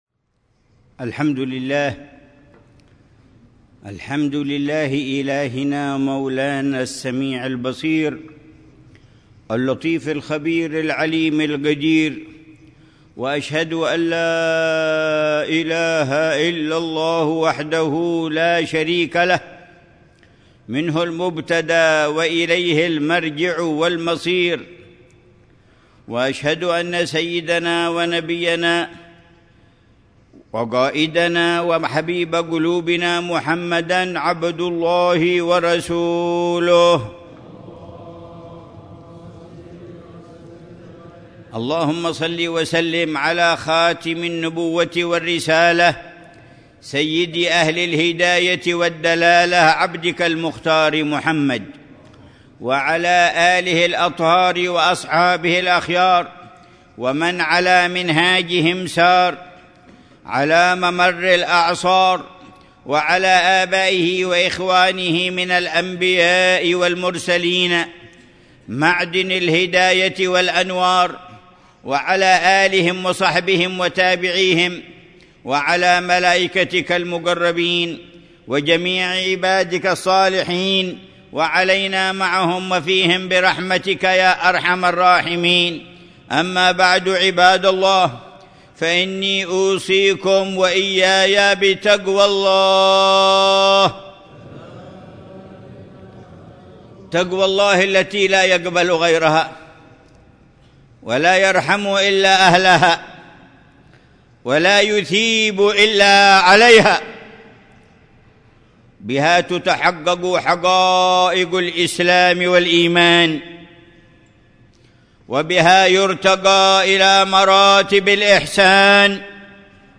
خطبة جمعة للعلامة الحبيب عمر بن محمد بن حفيظ في مسجد الجامع بمدينة سيؤون، وادي حضرموت، 30 جمادى الأولى 1447هـ بعنوان: